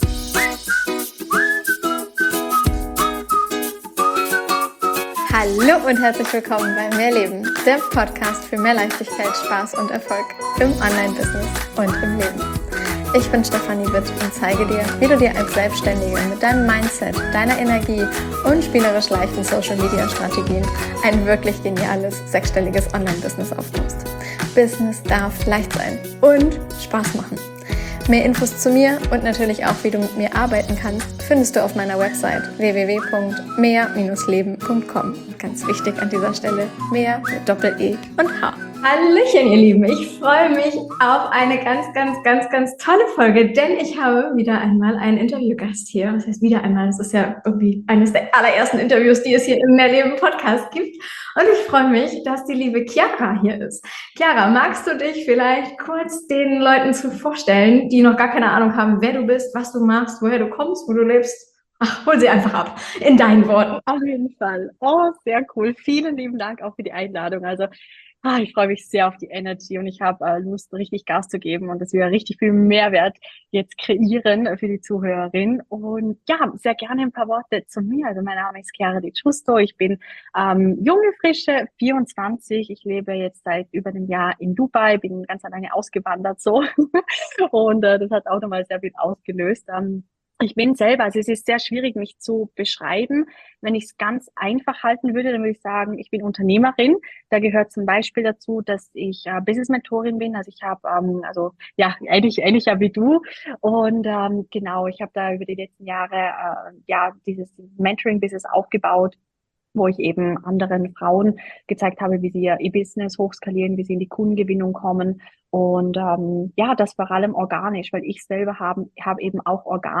#083 Wie du auf 7-stellige Jahresumsätze skalierst - Interview